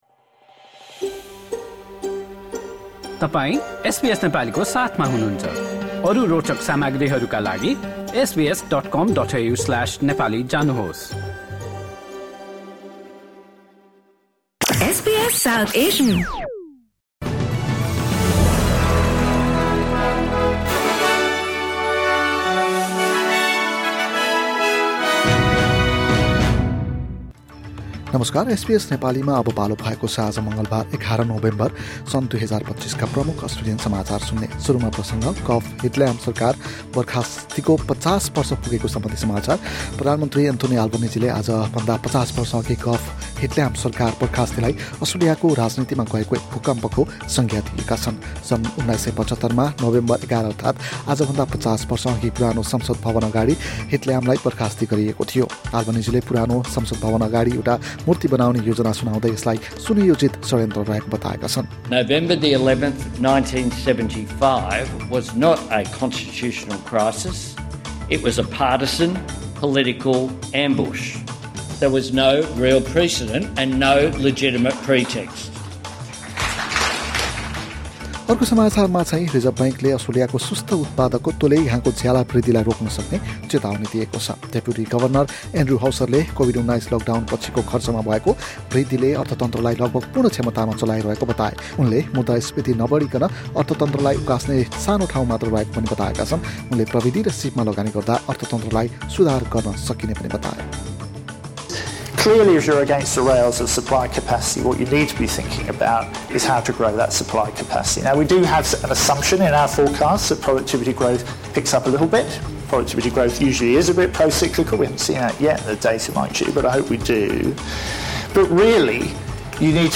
SBS Nepali Australian News Headlines: Tuesday, 11 November 2025